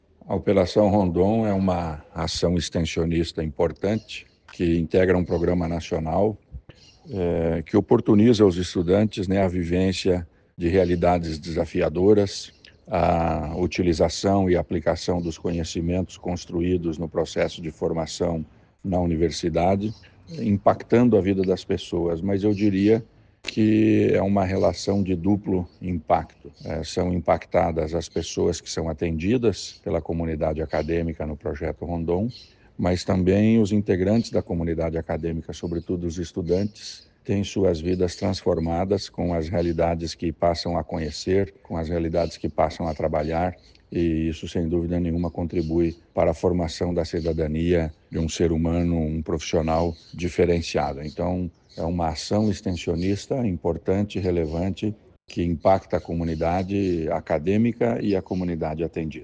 Sonora do secretário da Ciência, Tecnologia e Ensino Superior, Aldo Bona, sobre a abertura da Operação Pé Vermelho do Projeto Rondon